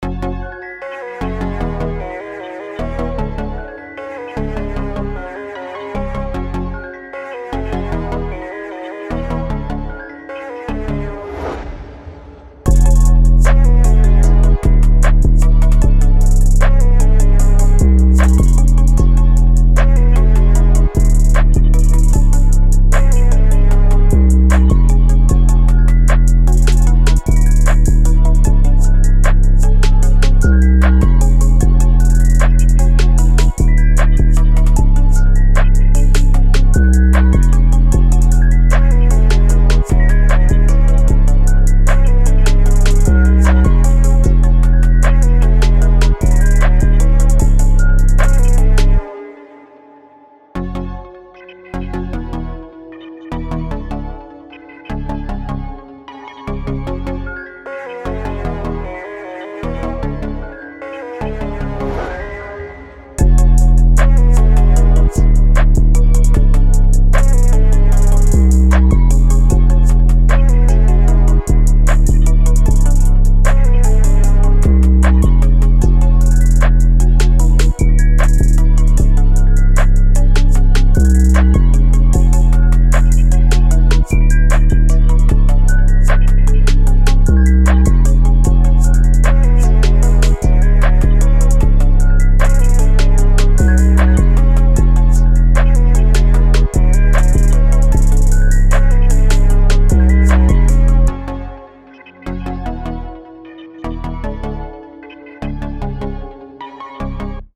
Cold, Dark, Energetic, Sexy
Drum, Heavy Bass, Piano, Strings